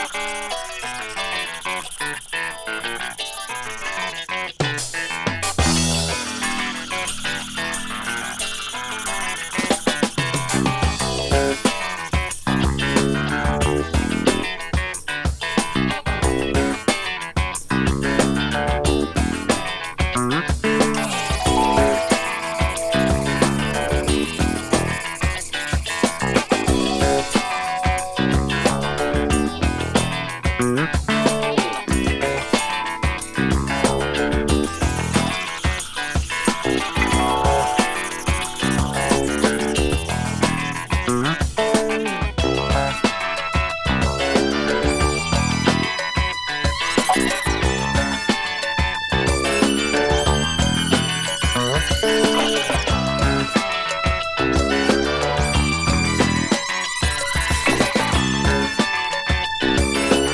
スペーシー・シンセの変拍子ジャズロック